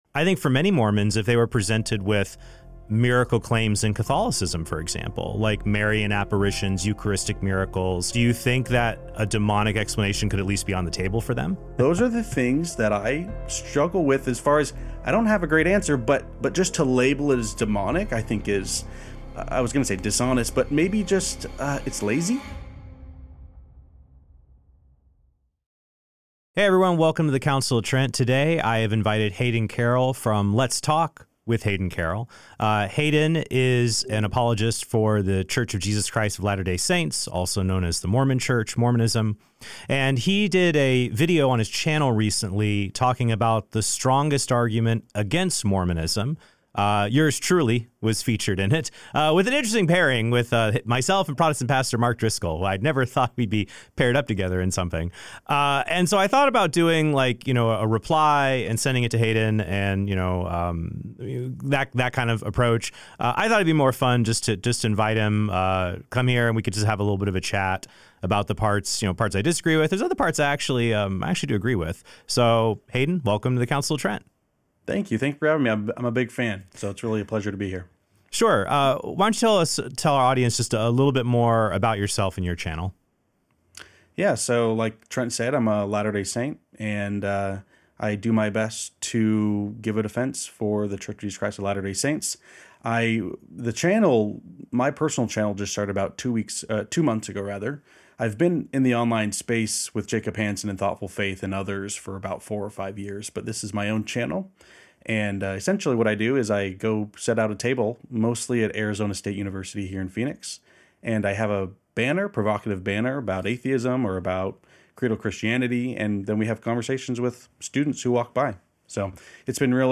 DIALOGUE: Is Mormonism Demonic?